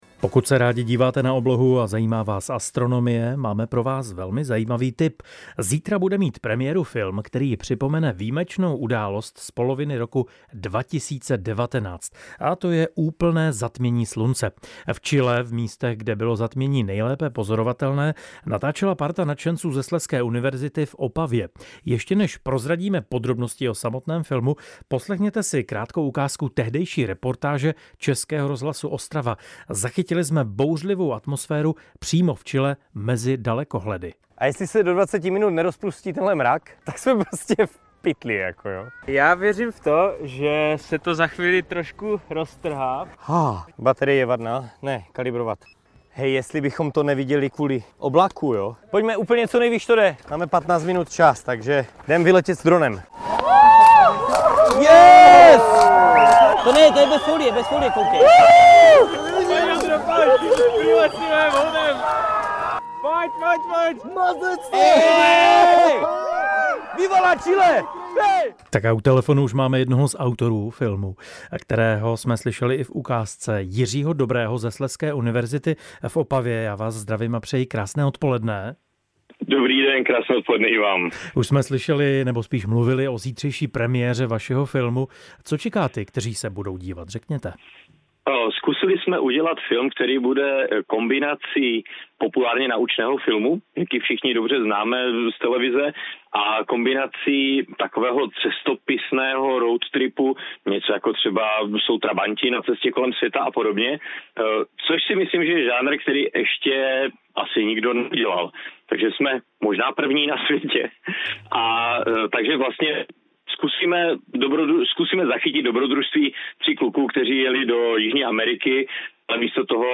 Poslechněte si níže záznam jeho živého vstupu s pozvánkou na premiéru, který jsme si z vysílání nahráli sami.